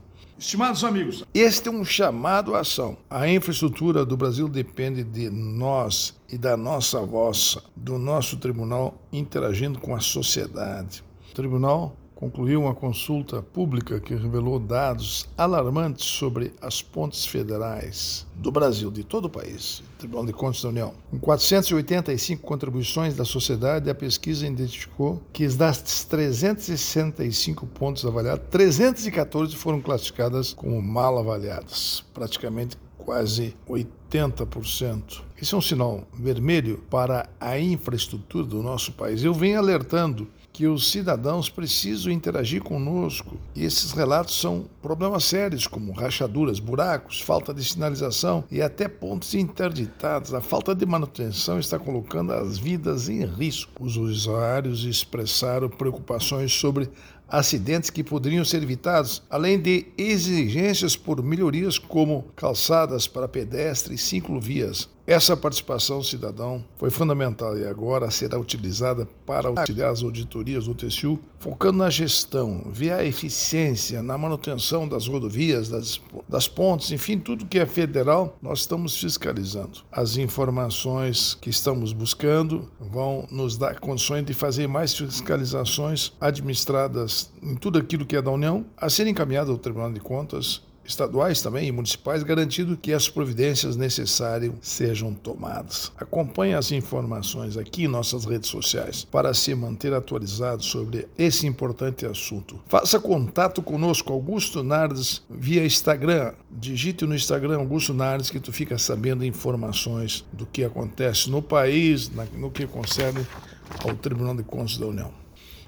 03-Ministro-prog-radio-chamado-a-acao.mp3